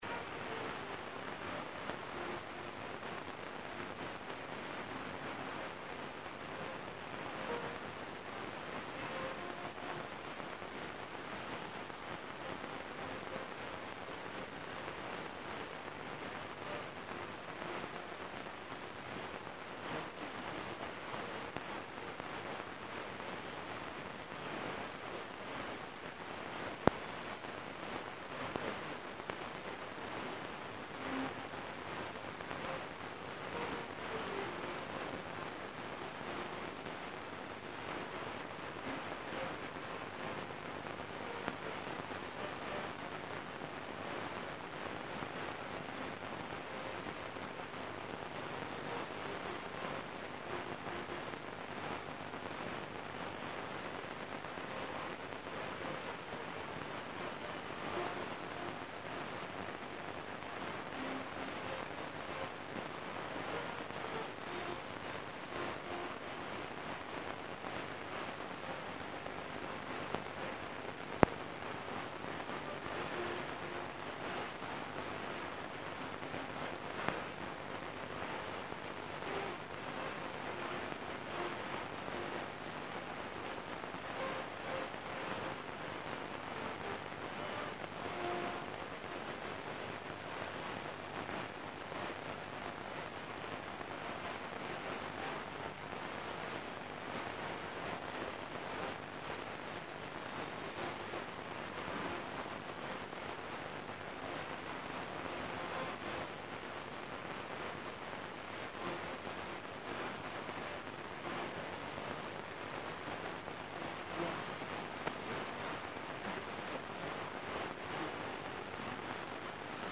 Весь процесс происходил на 9655 кГц 05.01.2011 г.
Поначалу, кроме атмосферных шумов и помех, не было слышно ровным счётом ничего, но где-то ближе к 11:47 (МСК) начала прорываться несущая.
позывной станции. Наиболее отчётливо он звучит на записи, начиная с отметки 01:00.
После этого некоторое время был слышен голос диктора, а потом стали передавать красивую песню в исполнении девушки. Надо заметить, что условия приёма постепенно улучшались и достигли своего пика к 12:08 (МСК).